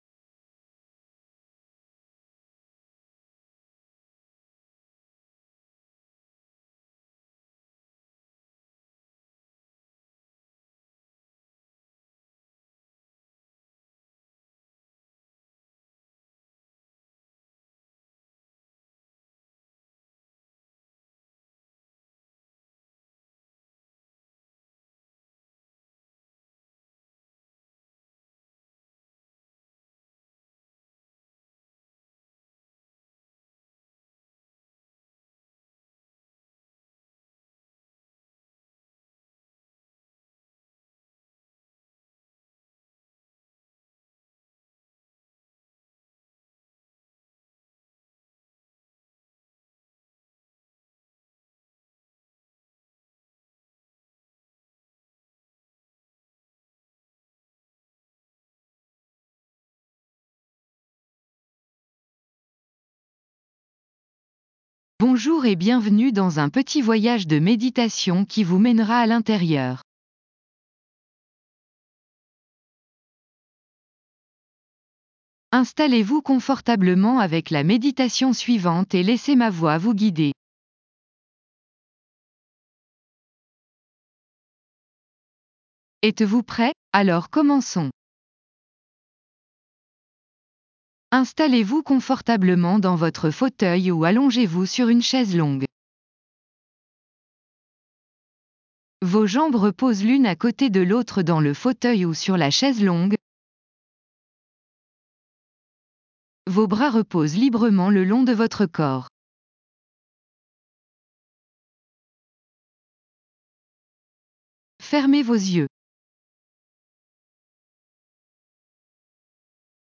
Maintenez votre concentration sur ce / ces points pendant que vous laissez la musique jouer sur vous.